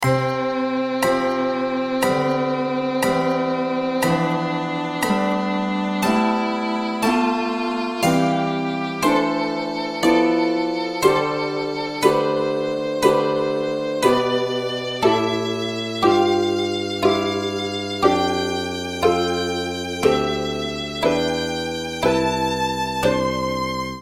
Mapping: Sonified using TwoTones Temperatura média global – piano com escala de frequência em Dó maior Emissões CO 2 per capita na China – Harpa com escala de frequência em Dó maior Emissões CO 2 per capita na Índia – Violino com escala de frequência em Dó maior Emissões CO 2 per capita no Reino Unido – Guitarra Elétrica com escala de frequência em Dó maior Emissões CO 2 per capita nos Estados Unidos – Mandolin com escala de frequência em Dó maior